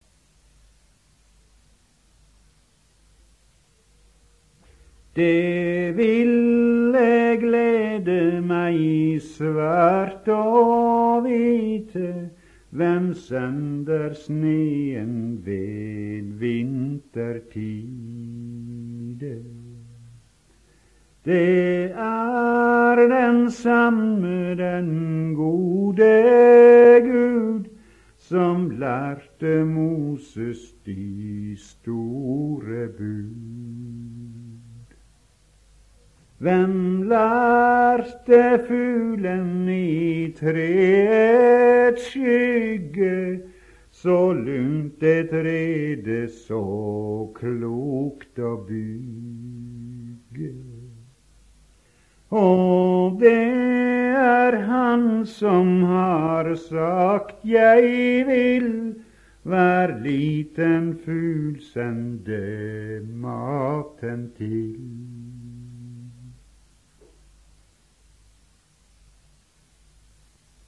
Nystev frå Setesdal